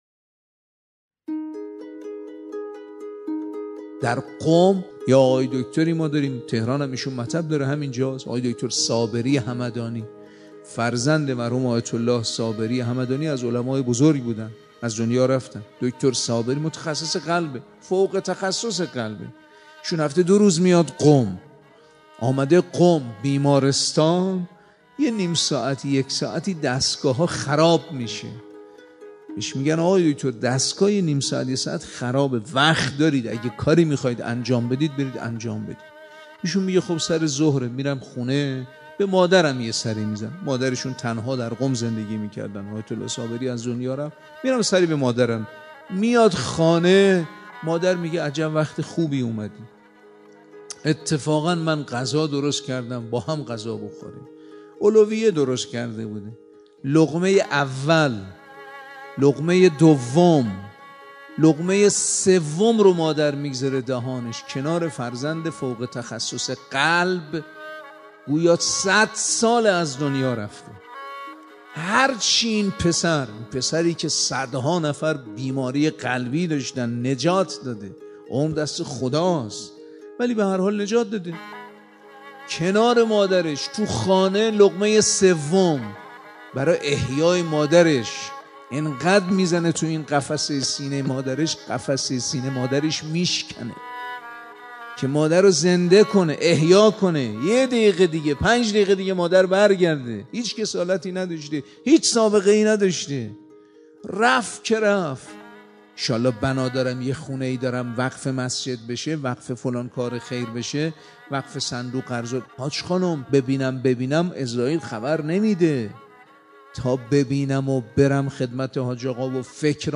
نواهنگ شنیدنی از سخنرانی